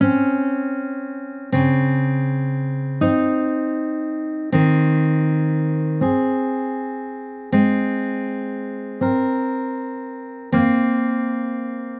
Here each measure starts with a minor interval. The root is always C.